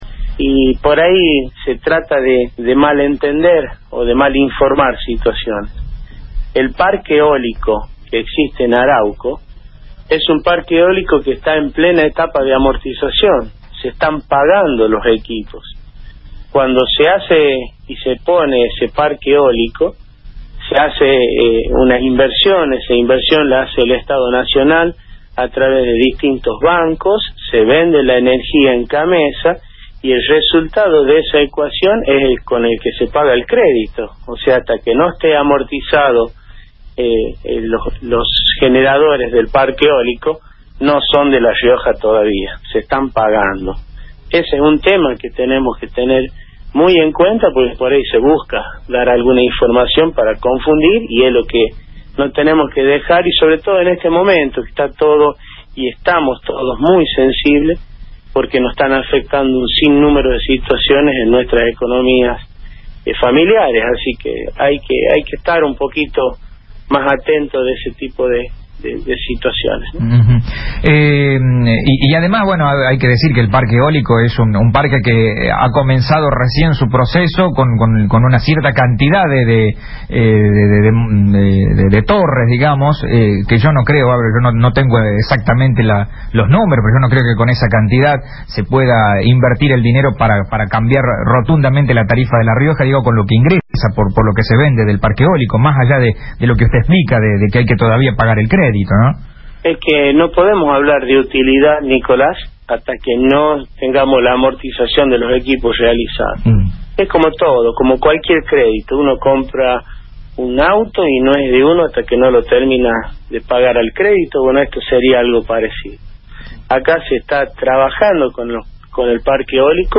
En declaraciones a Radio Fénix, el vicegobernador –desde Buenos Aires- informó que “el Parque Eólico (Arauco) está en la etapa de amortización”, por lo cual “se está pagando los equipos”.